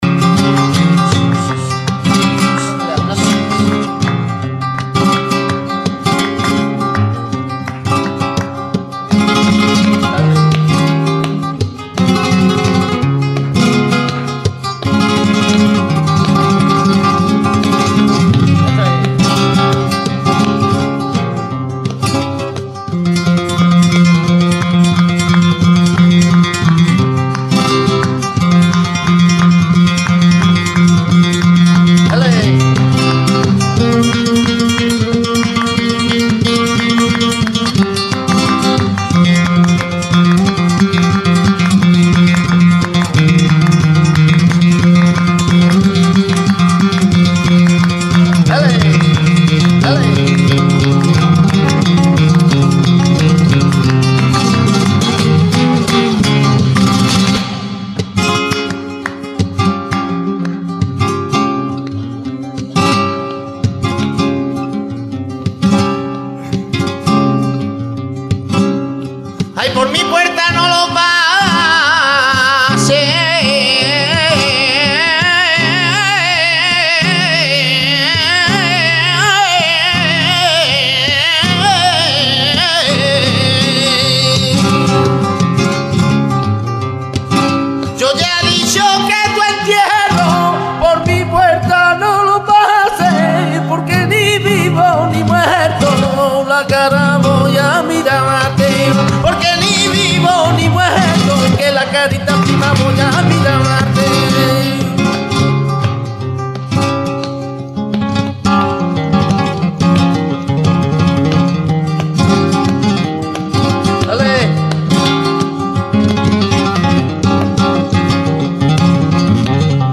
flamenco cantaor
Tangos
Guitarra
Uniek is de kracht en het bereik van zijn stem waarmee hij het publiek keer op keer op handen krijgt.
tangos.mp3